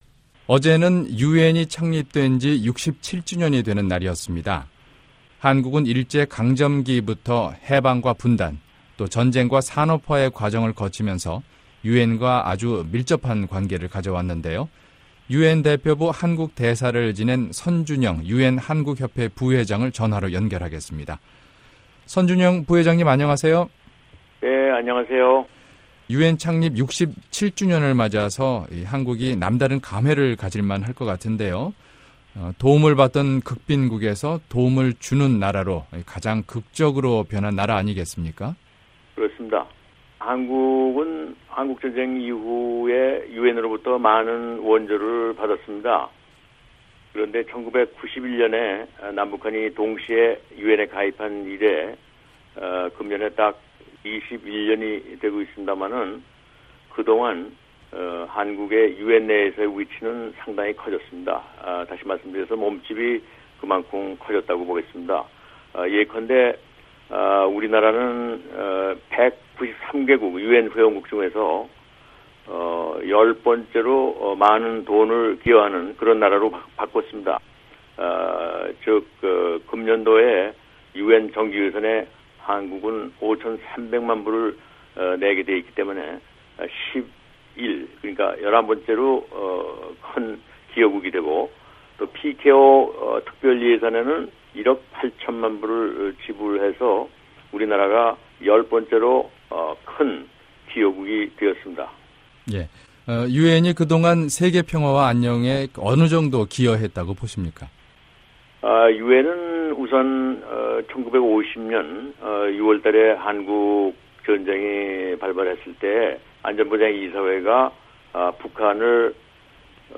[인터뷰] 선준영 전 유엔대표부 한국대사